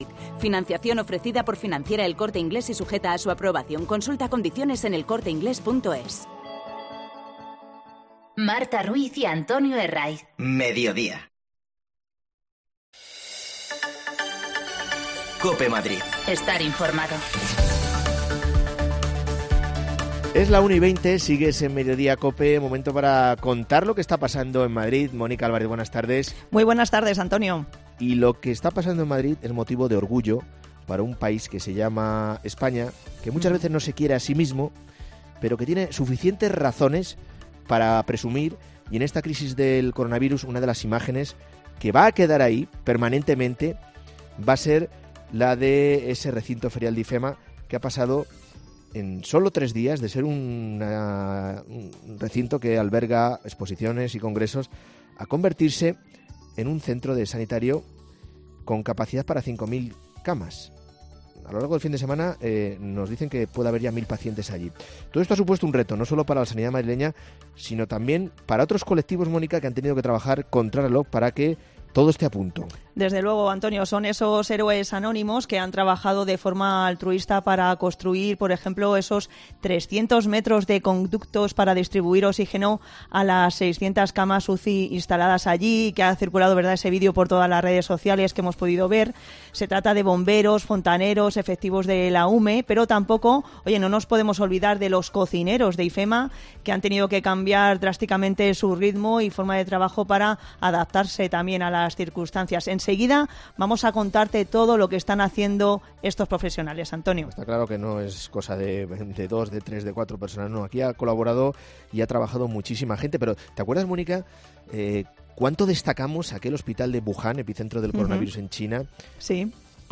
Escuha algunos testimonios de las personas que lo han hecho posible